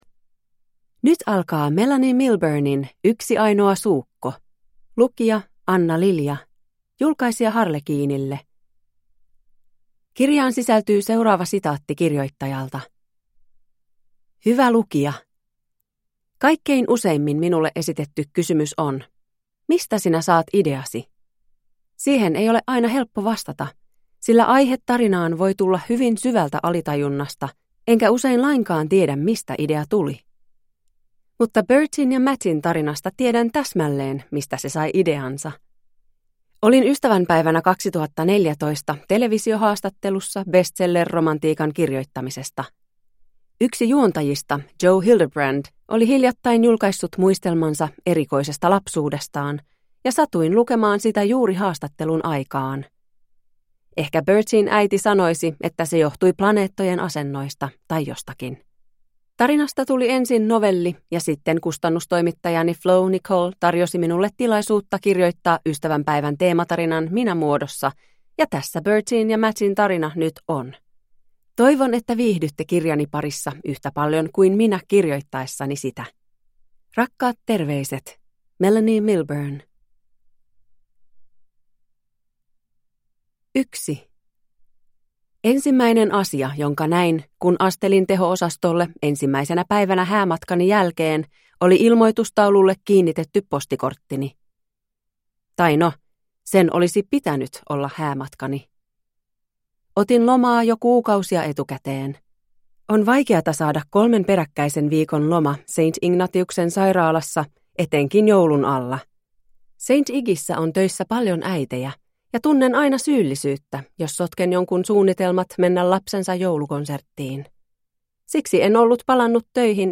Yksi ainoa suukko – Ljudbok